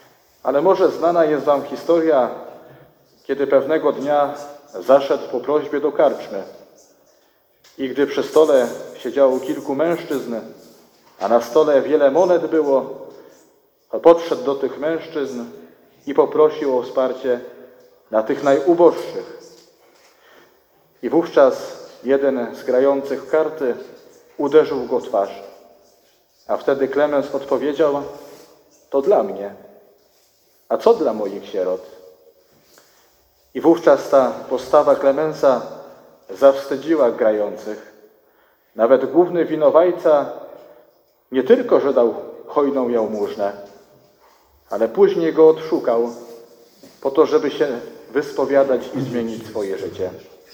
fragmenty homilii audio: